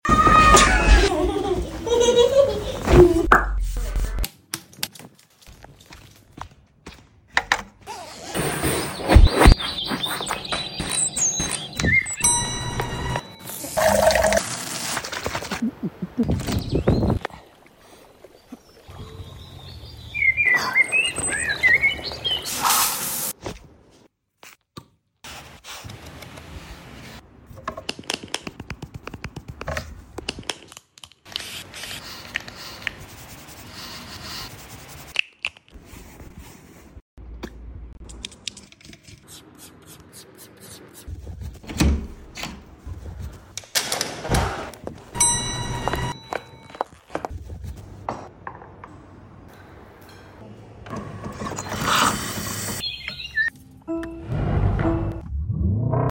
ASMR morning routine before work